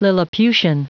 Prononciation du mot lilliputian en anglais (fichier audio)
Prononciation du mot : lilliputian